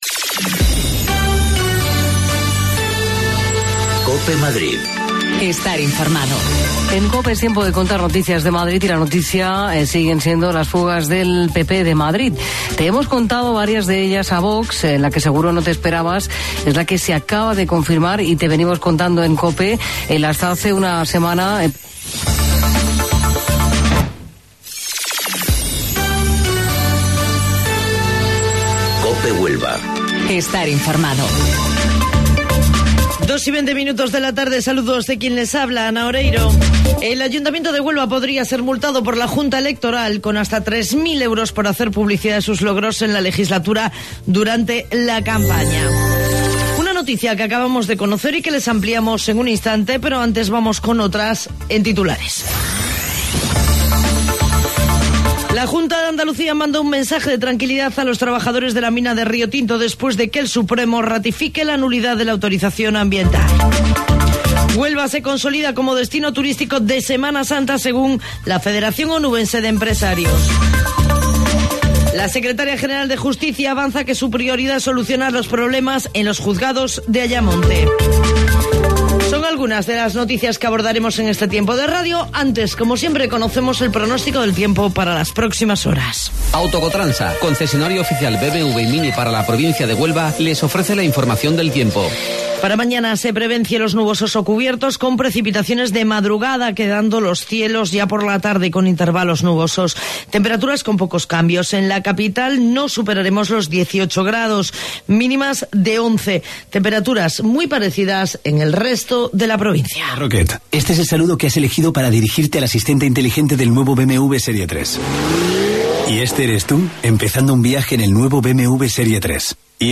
AUDIO: Informativo Local 14:20 del 24 de Abril